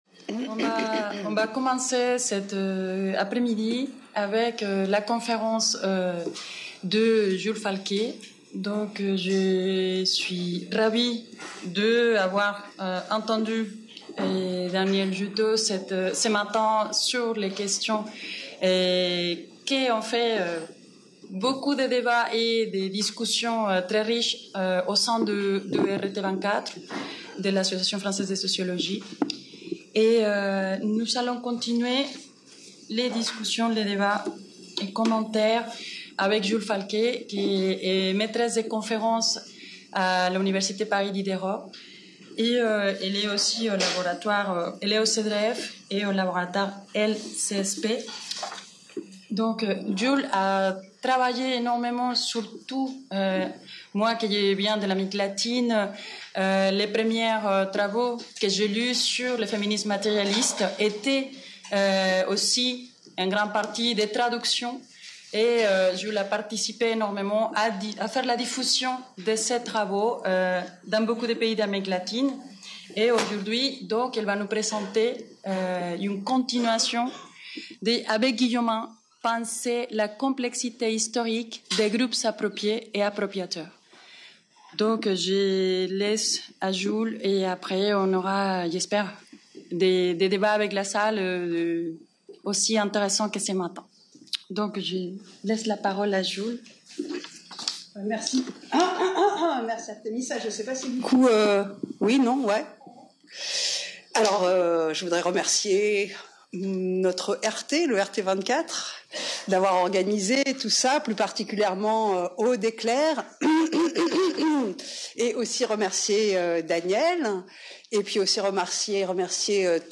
Conférence